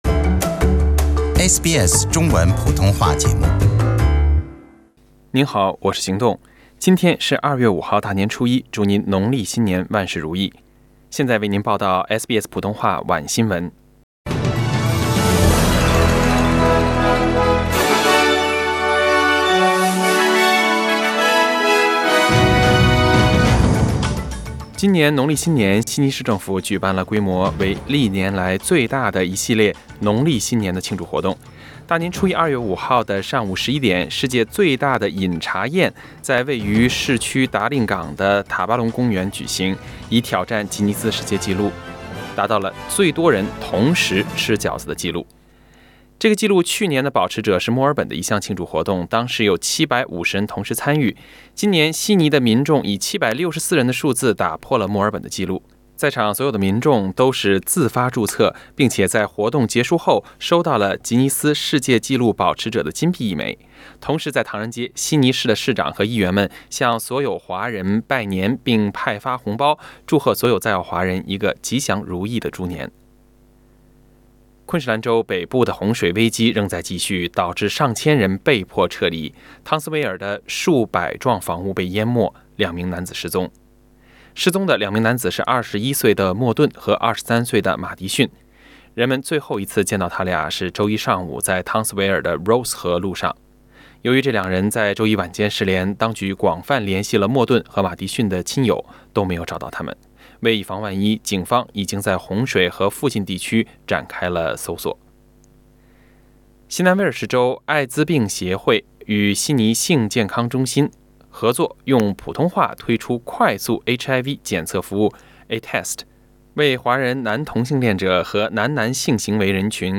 SBS晚新聞（2月5日）